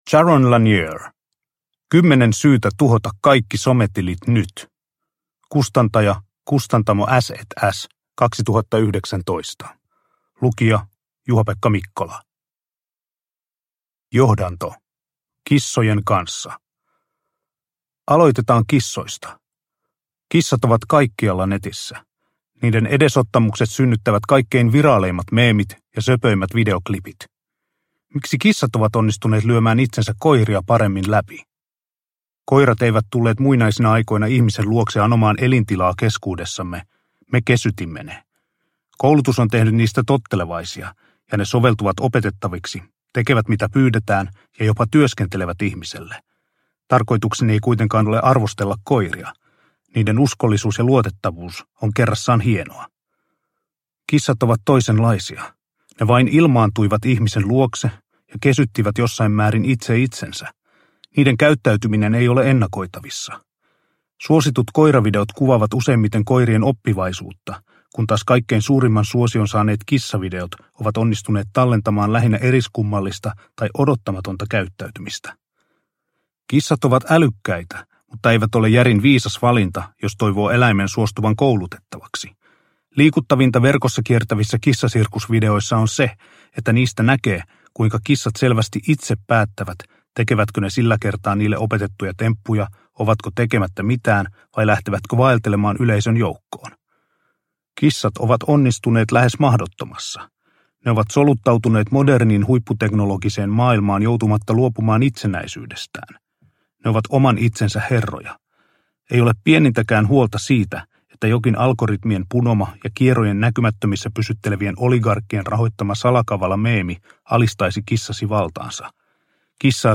10 syytä tuhota kaikki sometilit nyt – Ljudbok – Laddas ner